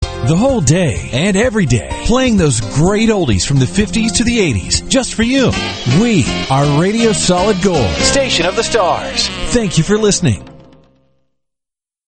RADIO IMAGING